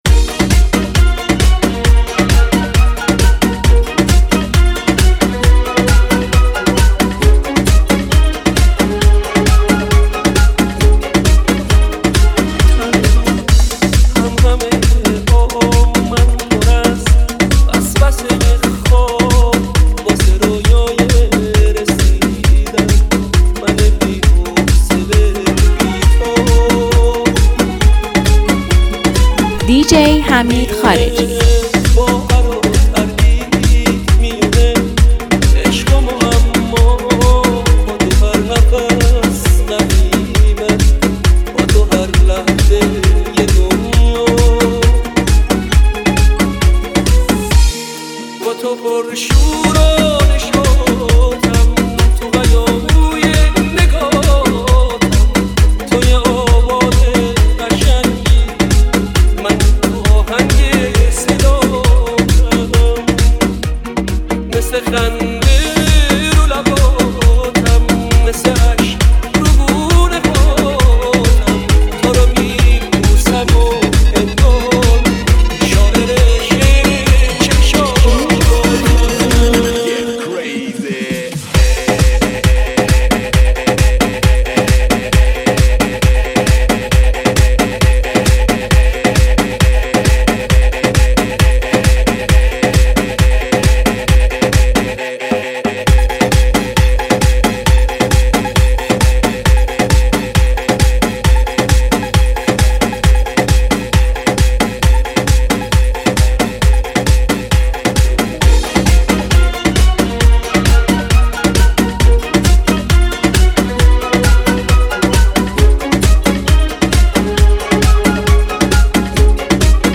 این ریمیکس، حس و حال نوستالژیکی خاصی رو براتون زنده می‌کنه.